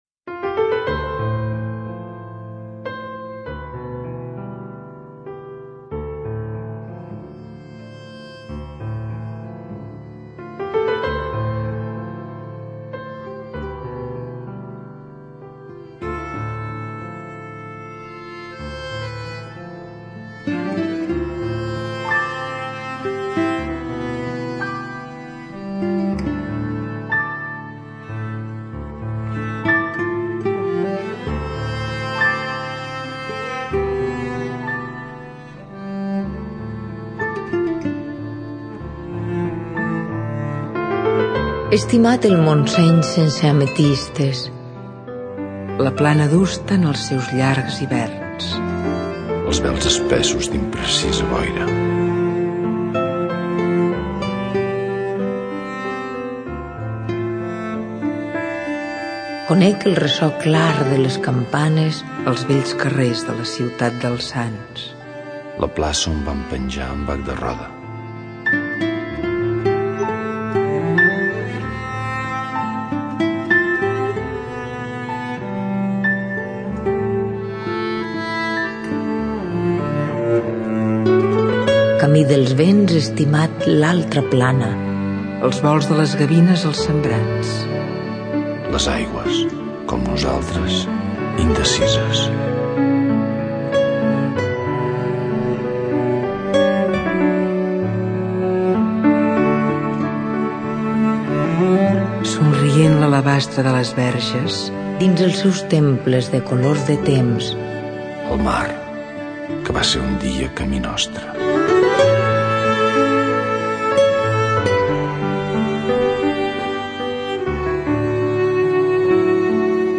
Quin paper hi juga la música per transmetre la diferència entre les dues planes?